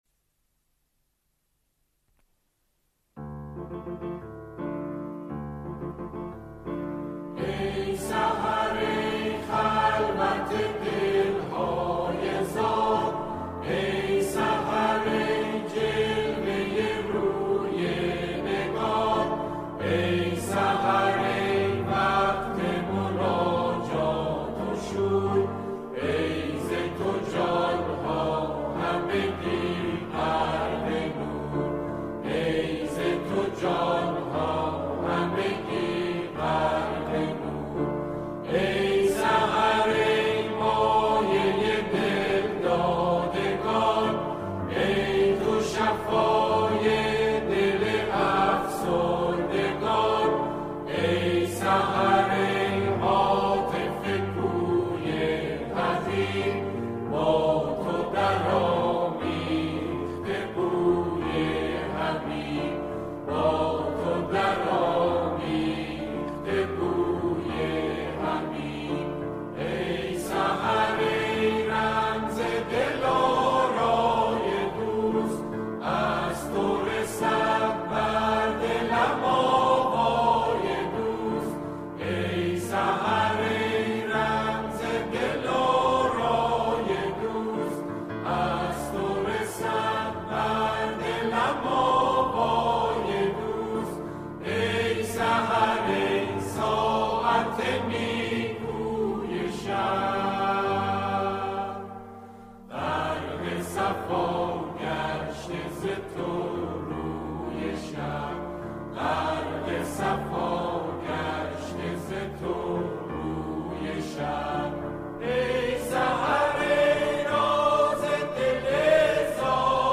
همخوانی شعری به گویش لری
گروهی از همخوانان